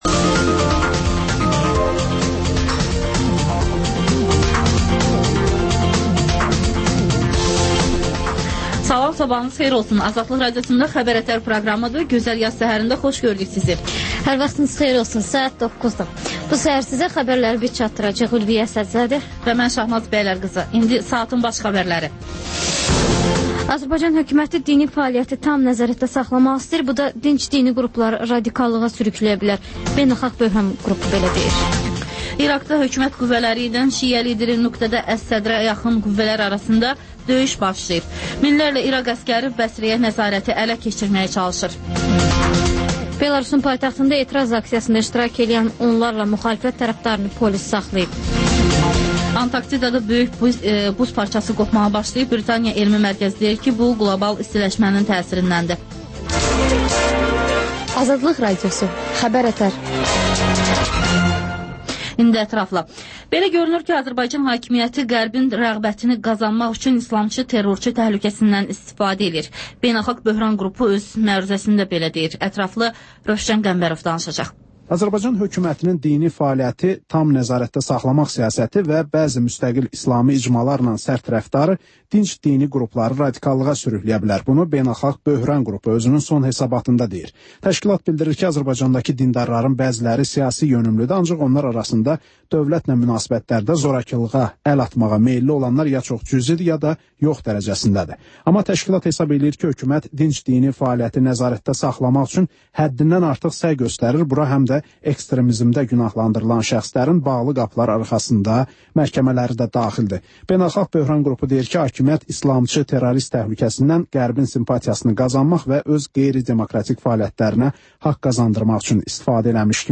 Xəbər-ətər: xəbərlər, müsahibələr, sonda XÜSUSİ REPORTAJ rubrikası: Ölkənin ictimai-siyasi həyatına dair müxbir araşdırmaları